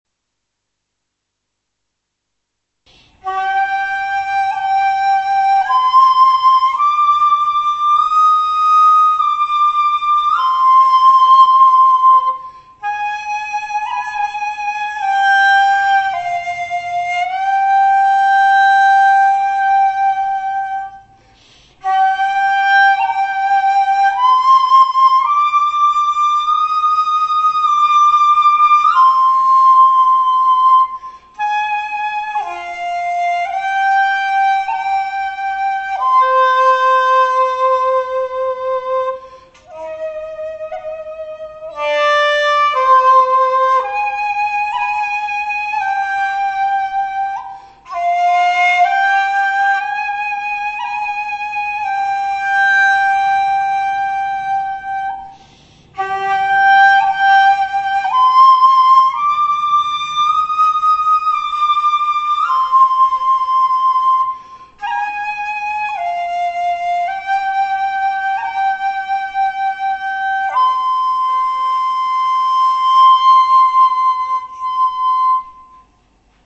尺八練習曲
荒城の月 ｏｋ １コーラス（高い吹き方）